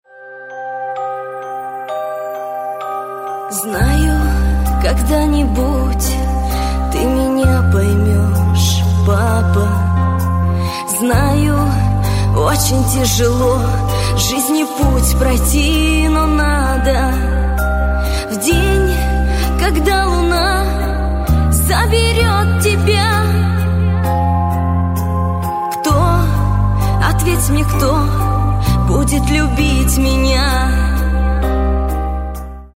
Спокойные И Тихие Рингтоны
Шансон Рингтоны